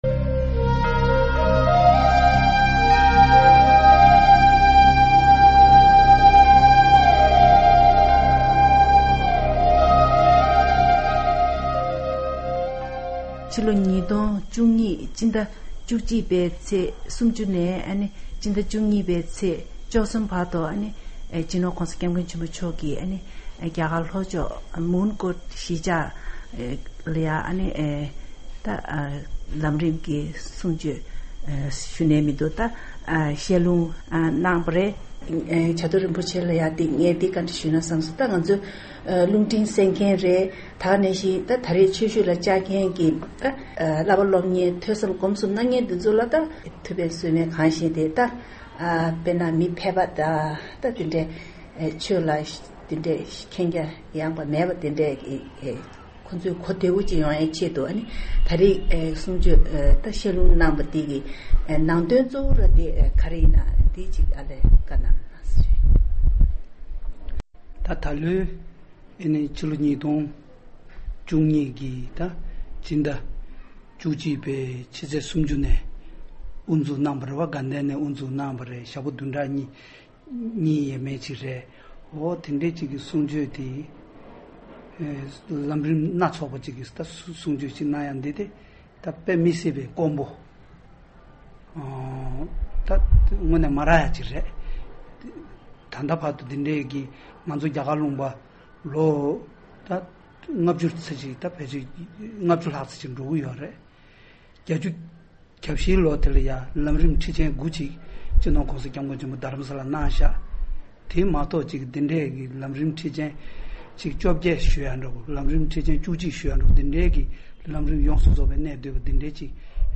གནས་འདྲི་ཞུས་པ་ཞིག་གསན་གྱི་རེད།